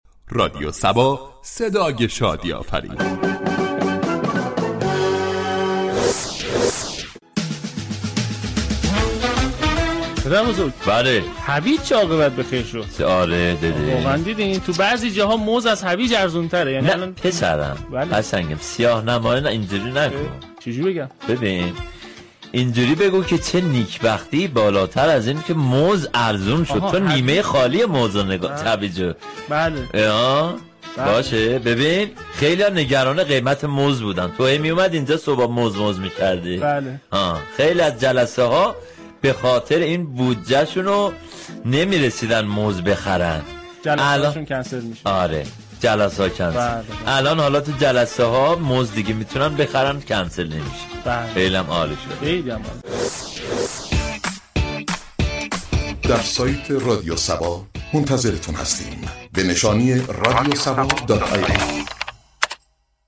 شوخی صبحگاهی رادیو صبا در مورد افزایش قیمت هویج در برابر موز كه قیمت هر كیلو 30 هزار تومان را شكست و توانست از موز به قیمت هر كیلو 28 هزار تومان پیشی بگیرد